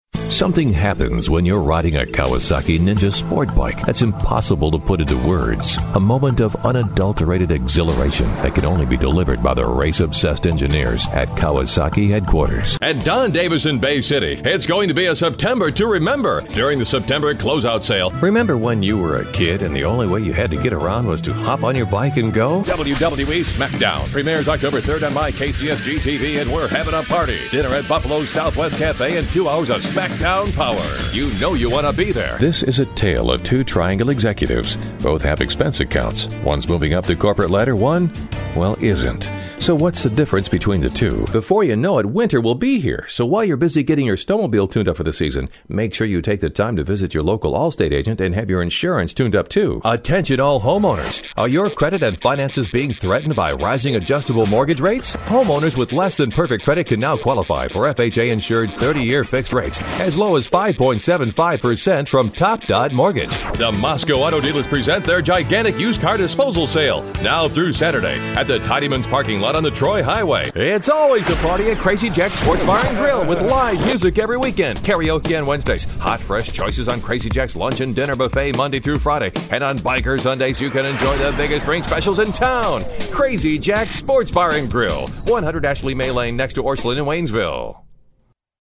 123AudioAds Voiceover Artists
English - Male